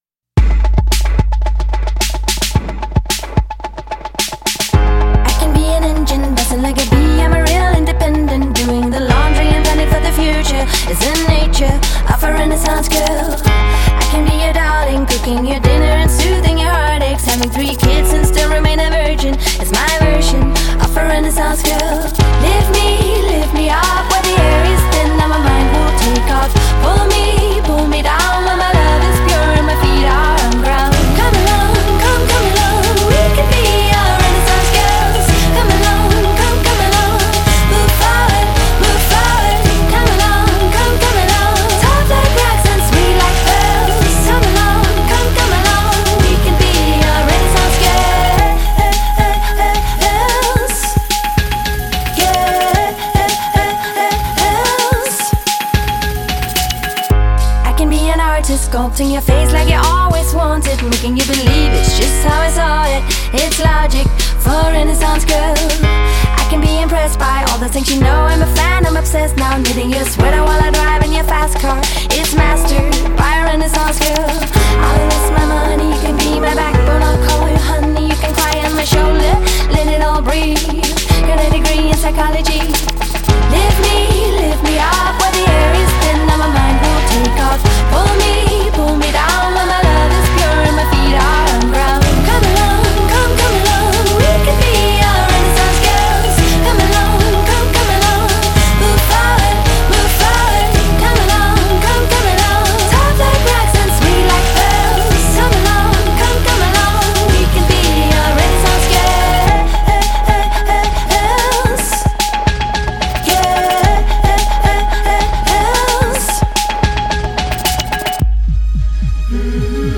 dizzying lyrical spit